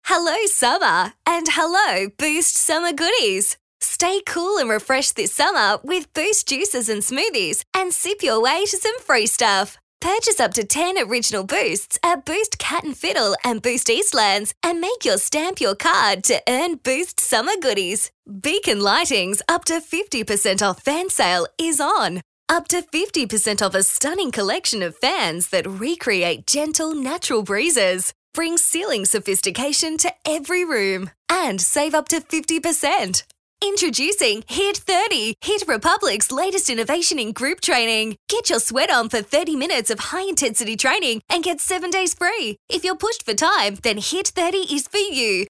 • Hard Sell
• Young
• Bright
• Fresh & Friendly
• Neumann TLM 103
• Own Home Studio